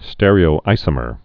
(stĕrē-ō-īsə-mər, stîr-)